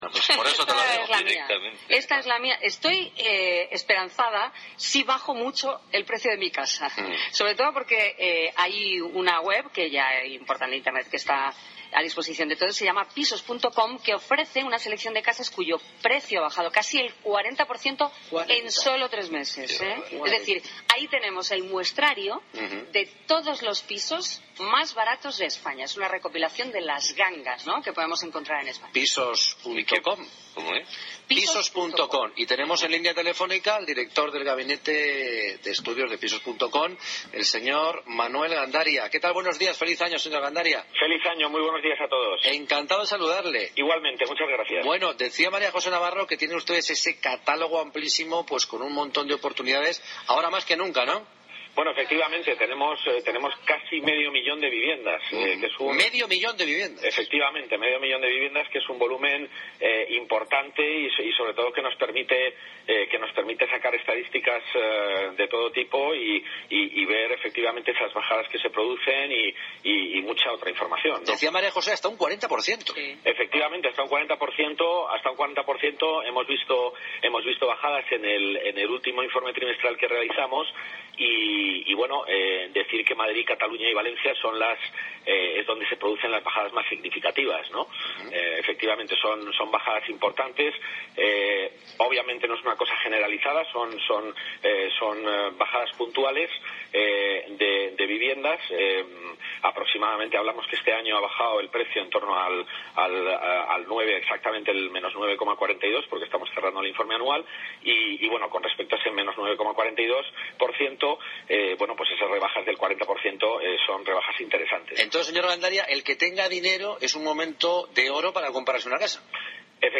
Entrevista CADENA COPE